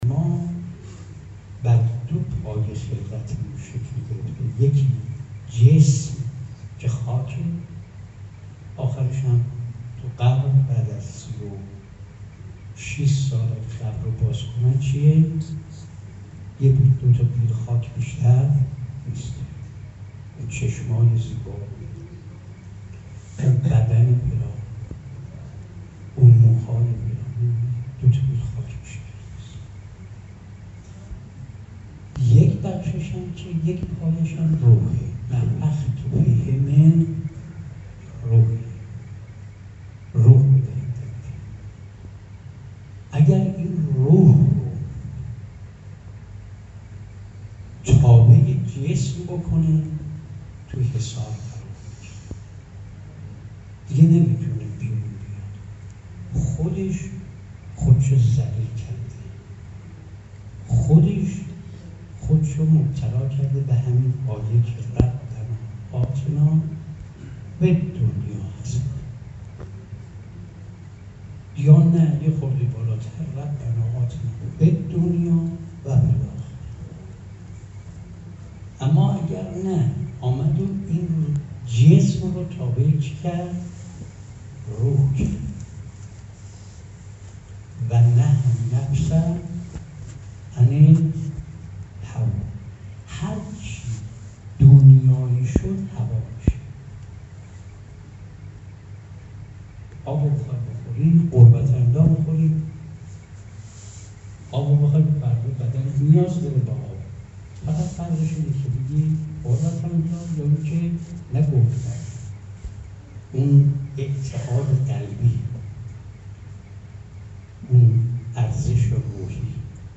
به گزارش خبرنگار سیاسی خبرگزاری رسا، حجت الاسلام نصرت الله هاتفی نماینده سابق مجلس شورای اسلامی پیش از ظهر امروز در جمع طلاب مدرسه علمیه آیت الله مجتهدی تهرانی(ره) با اشاره به هدف خلقت انسان گفت: انسان برای هدف مشخصی آفریده شده است و متأسفانه گاهی همه زندگی خود را در اهداف مادی محصور می کند.